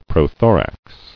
[pro·tho·rax]